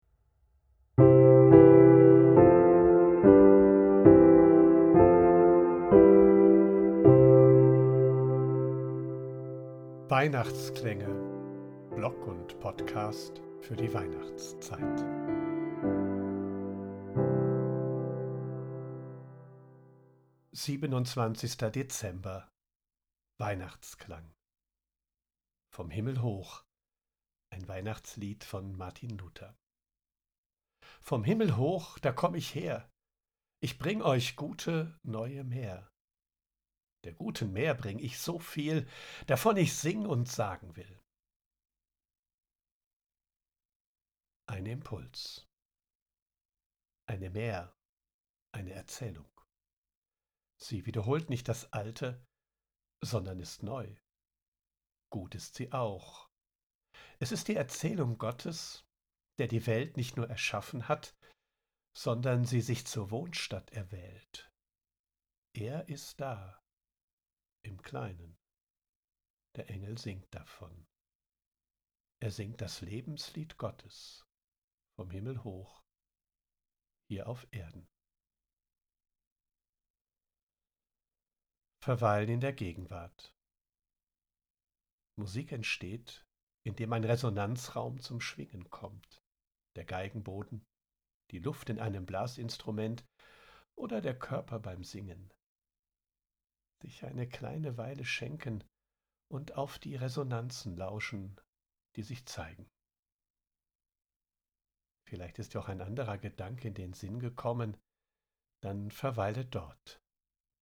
00:00:00 Weihnachtsklang: Vom Himmel hoch